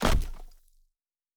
Stone 11.wav